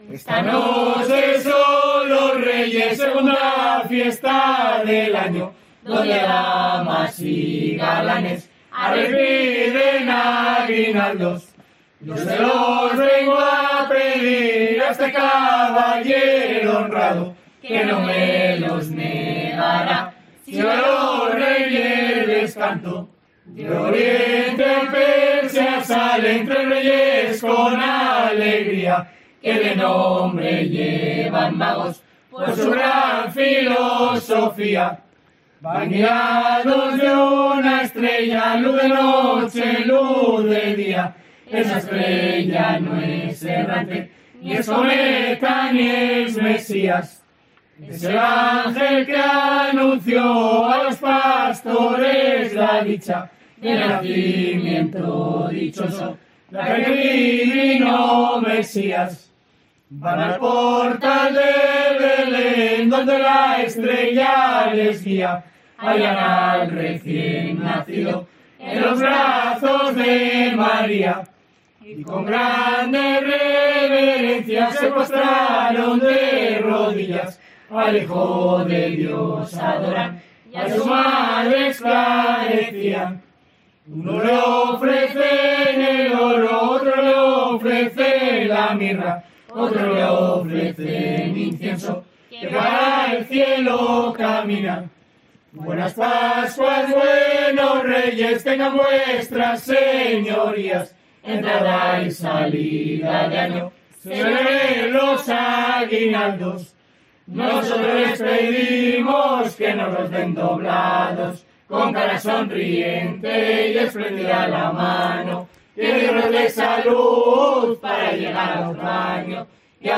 En esta localidad de Ribamontán al Monte, los más jóvenes acuden a las calles para dedicar un cántico bicentenario a los vecinos.
Desde la iglesia, los jóvenes entonan cánticos que relatan el nacimiento de Jesús y la visita de los Reyes Magos para adorarlo.
A medida que el grupo avanza por las calles de este enclave trasmerano, las melodías resuenan en el aire, llevando consigo una mezcla de alegría y solemnidad.